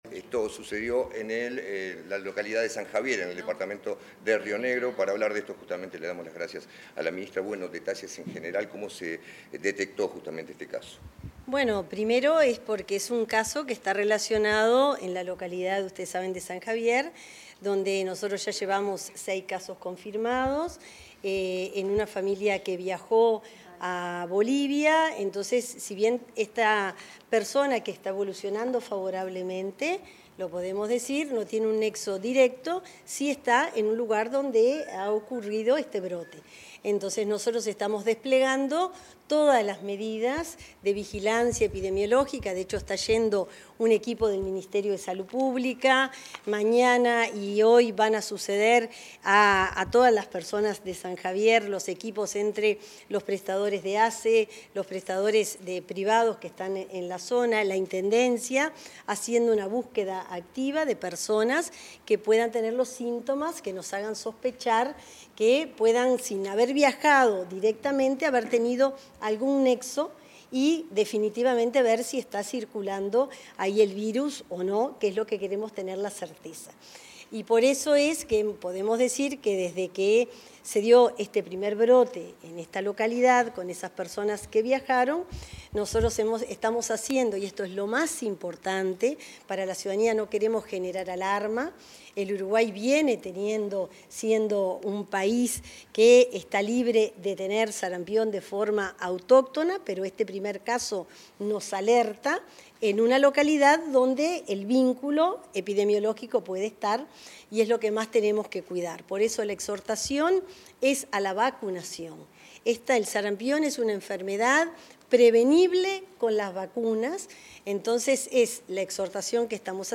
Ministra Lustemberg informó en rueda de prensa sobre estado de personas afectadas por sarampión